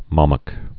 (mŏmək)